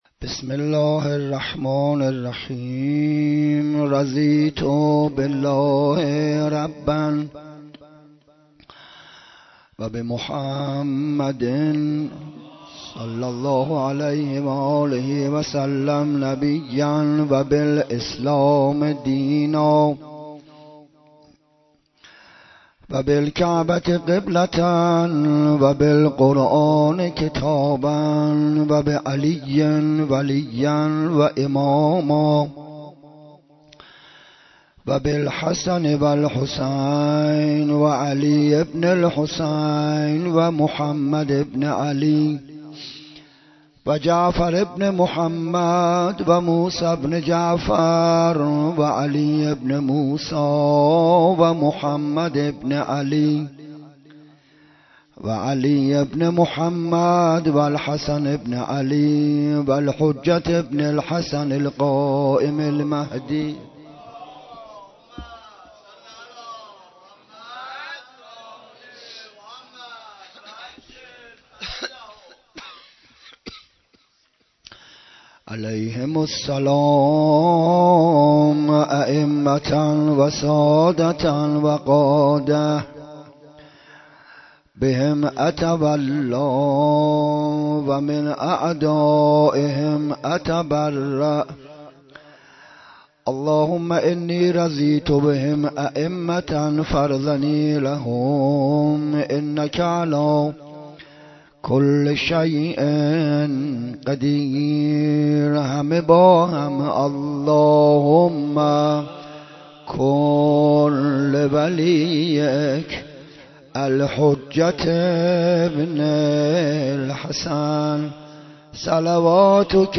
سخنرانی محرم 96